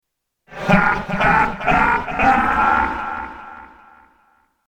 Ha ha ha